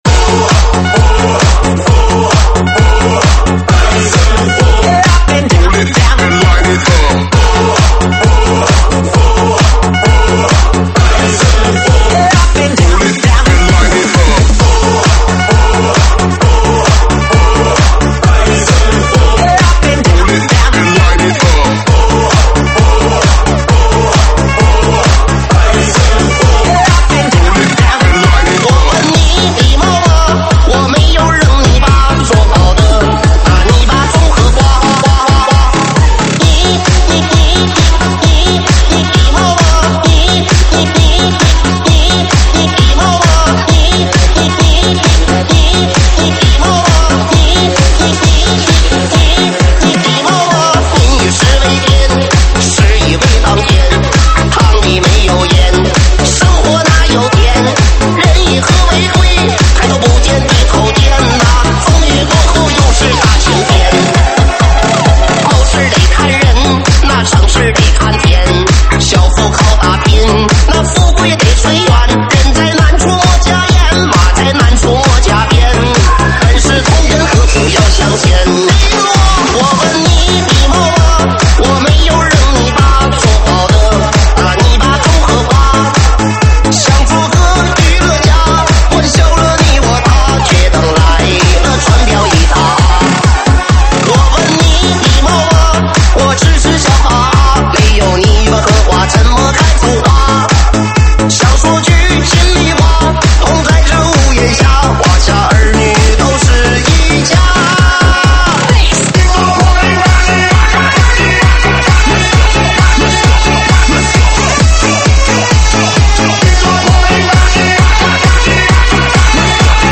舞曲类别：周榜单